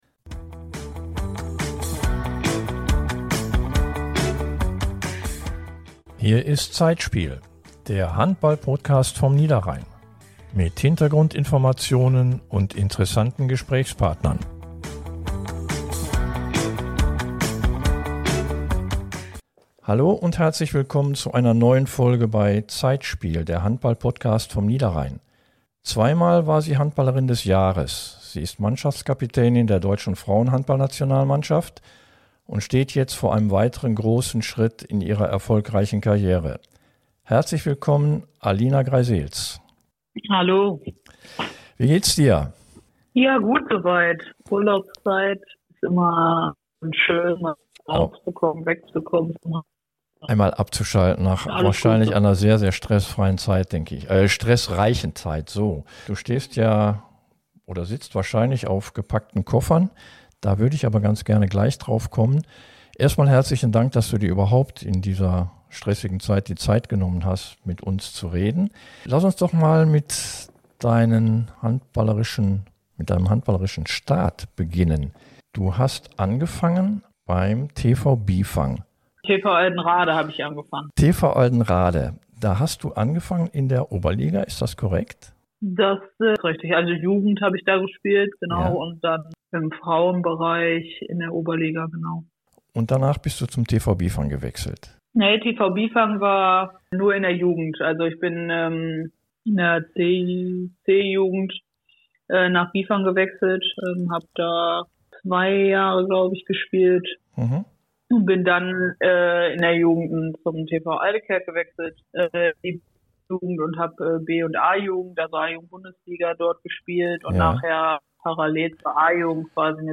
Zweimal war sie Handballerin des Jahres, sie ist Mannschaftskapitänin der deutschen Frauen-Handballnationalmannschaft und steht jetzt vor einem weiteren großen Schritt in ihrer erfolgreiche Karriere. Freut Euch auf ein interessantes Gespräch mit Alina Grijseels.